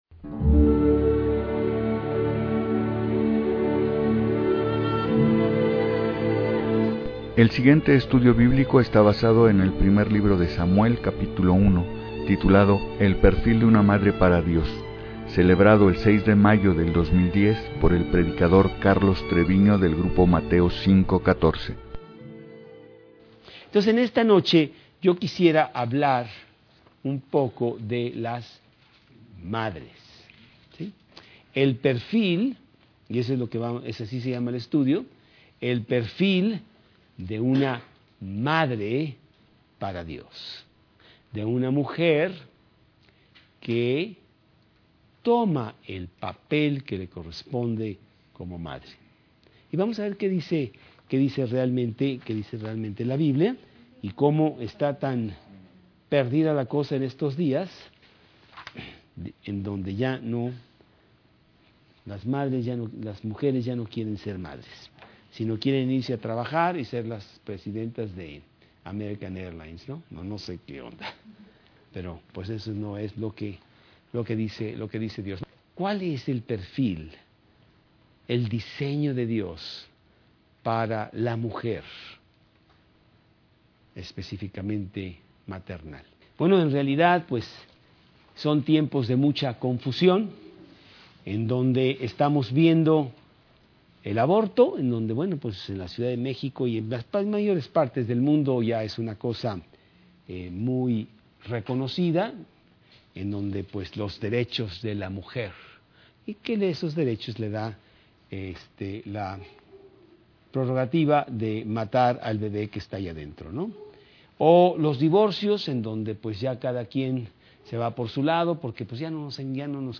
2010 El Perfil de una Madre para Dios Preacher